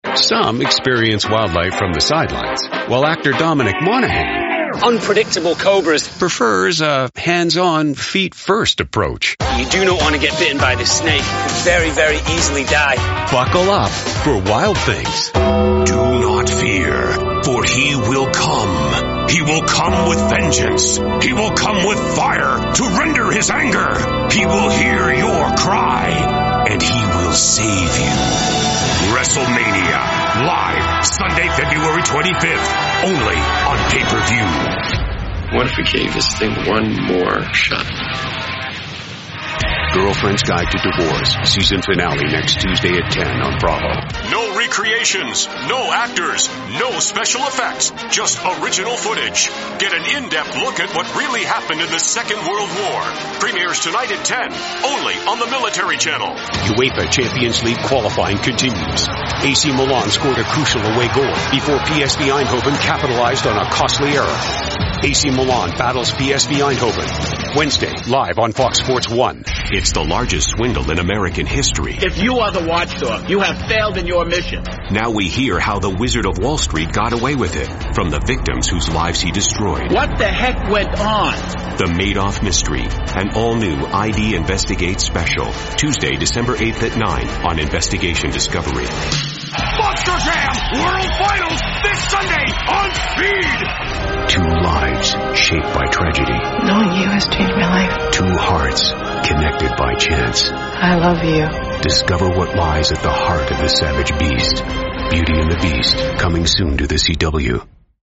English (Canadian)
TV Shows
Sennheiser MKH 416 microphone
Custom built voice booth
BaritoneBassDeep
ConfidentGroundedSeriousAuthoritativeConversationalCorporateExperienced